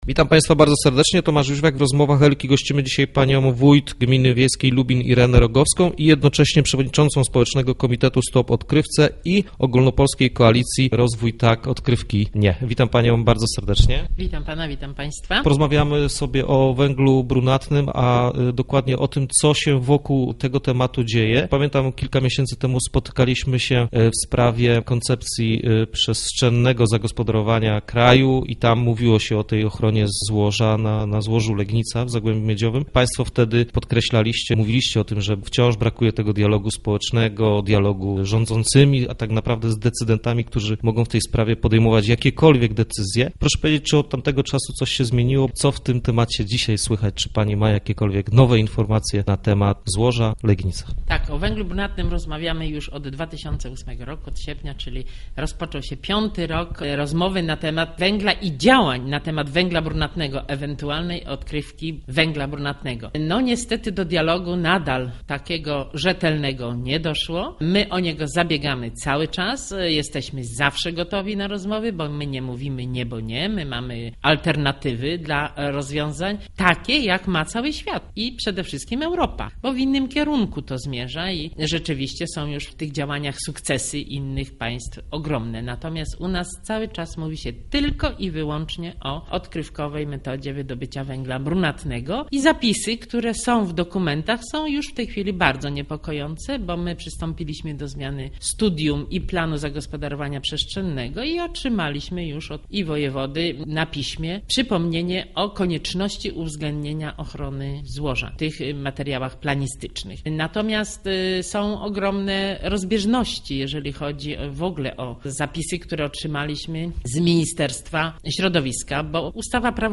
Naszym gościem była wójt gminy wiejskiej Lubin, Irena Rogowska, która przewodniczy Ogólnopolskiej Koalicji &bdqu...